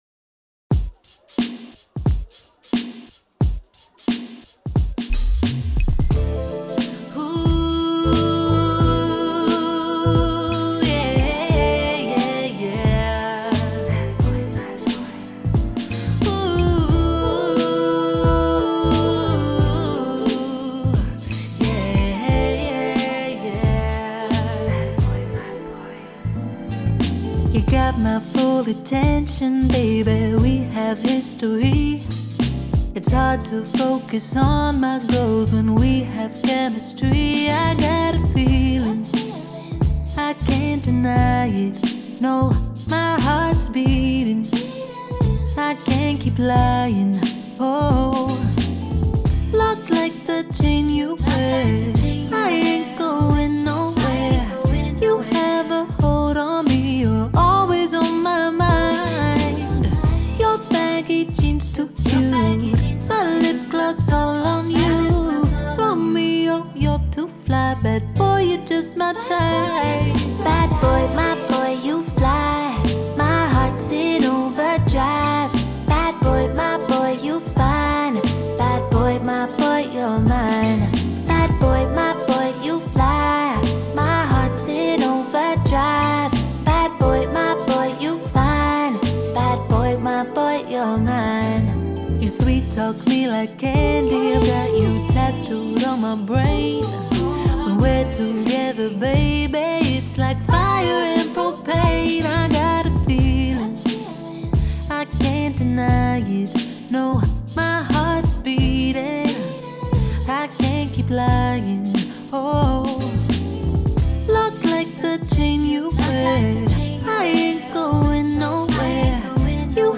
90s, R&B, Pop
A minor